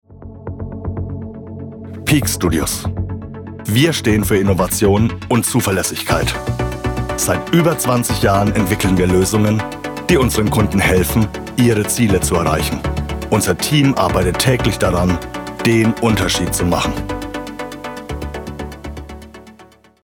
Voiceovers: „Unternehmesprofil“